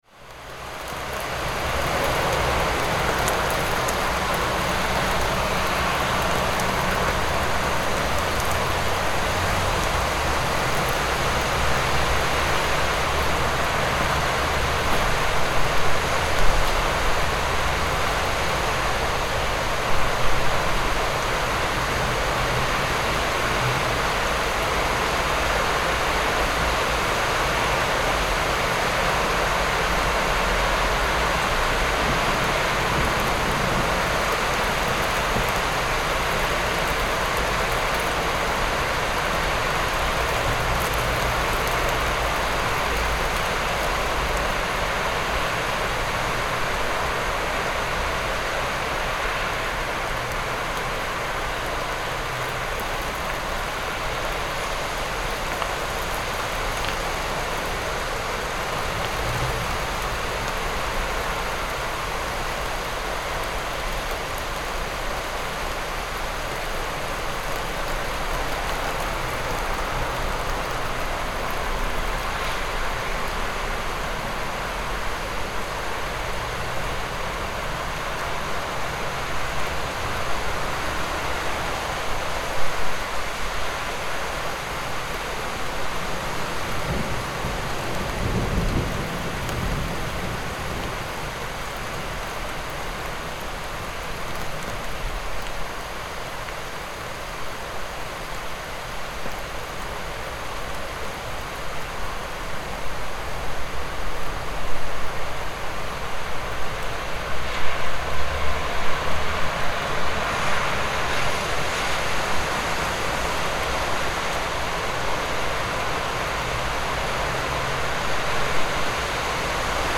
Rainy-busy-city-street-sound-effect.mp3